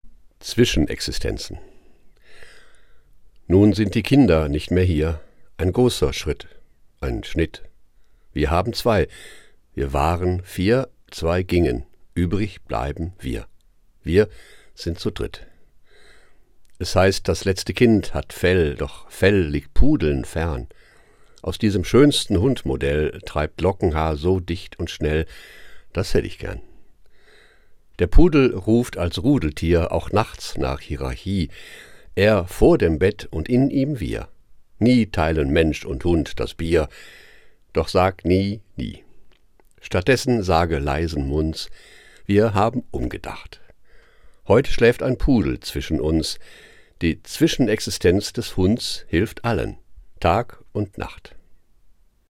Das radio3-Gedicht der Woche: Dichter von heute lesen radiophone Lyrik.
Gelesen von Tomas Gsella.